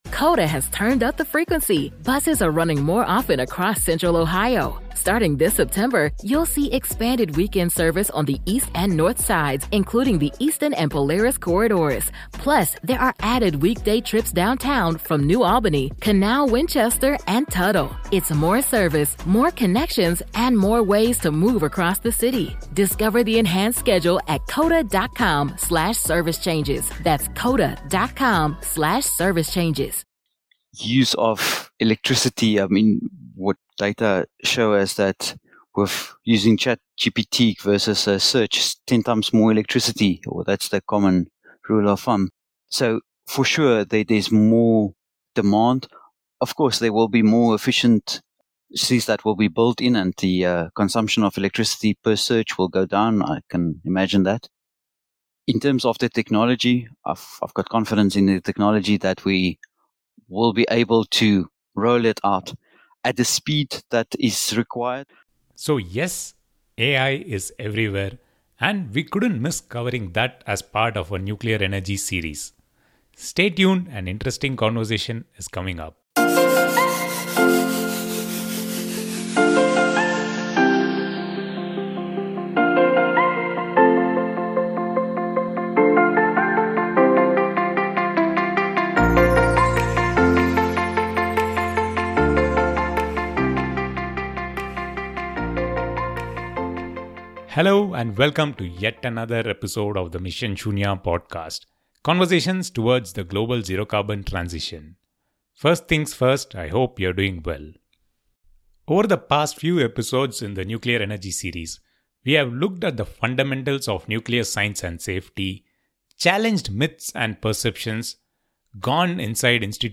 Mission Shunya (Shunya=Zero) is all about the transition to a zero carbon economy. The podcast features conversations related to clean-tech and sustainability.